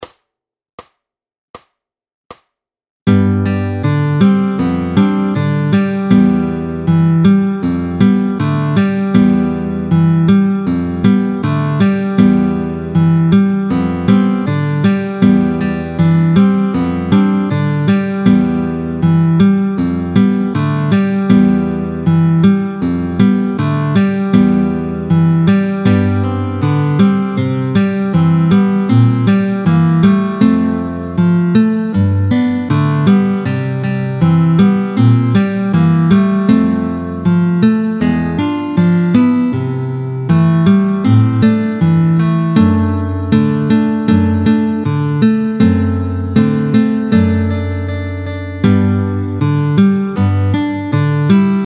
démo guitare